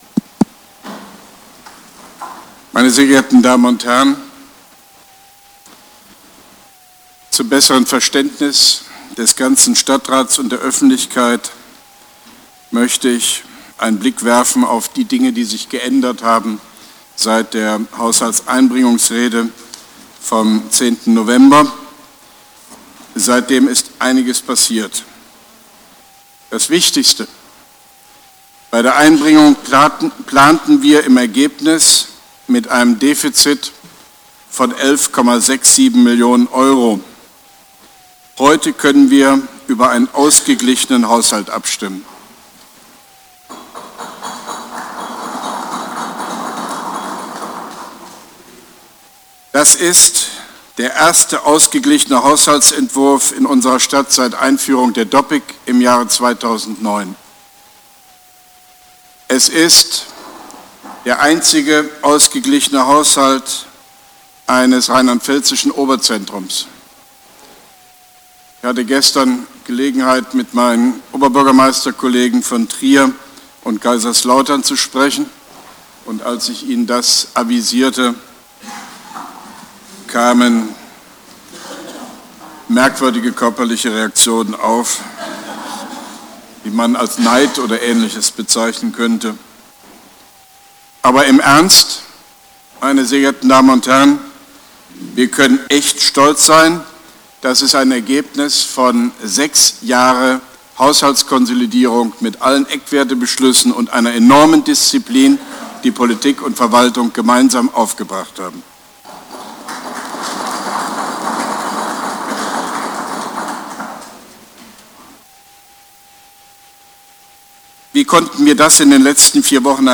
Reden/audio u. print JoHo |
OB Hofmann-Göttig erläutert im Koblenzer Stadtrat den Weg zum Plebiszit über flächendeckende Einführung von Ortsbeiräten und Ortsvorstehern, Koblenz 02.02.2017